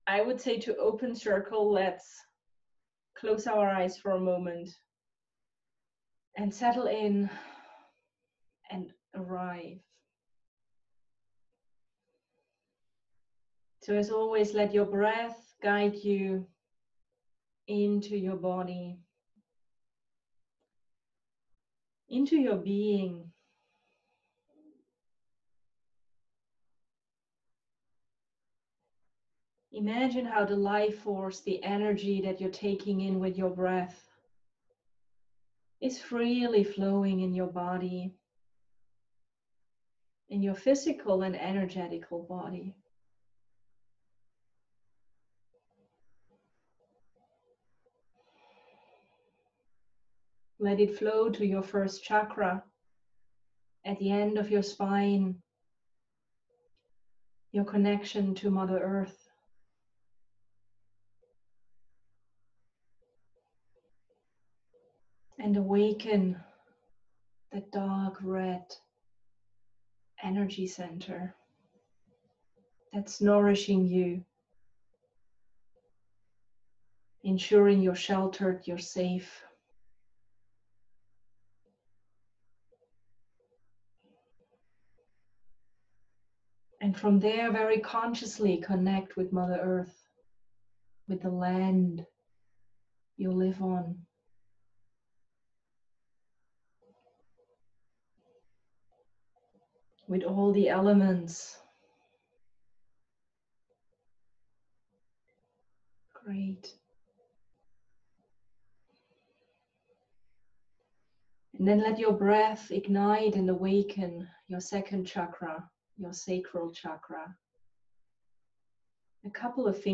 Chakra-meditation-_english_.mp3